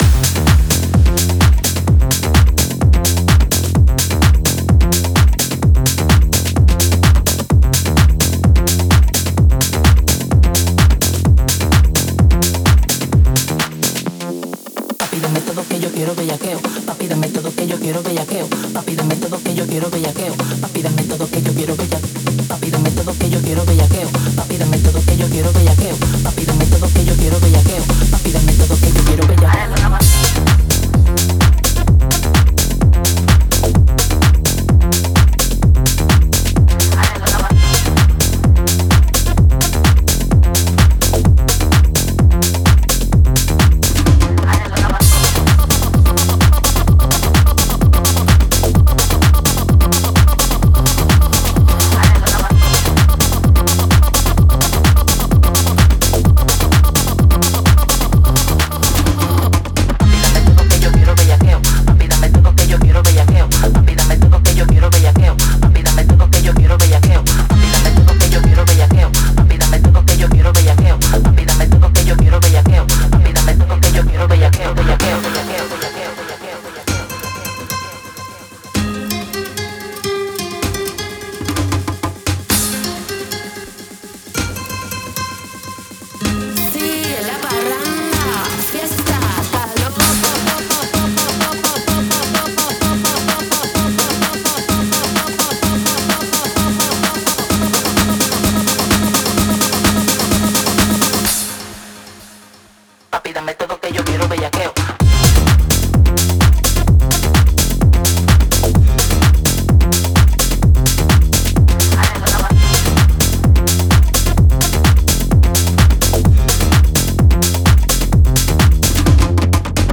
Genre: Tech-House, Latin-House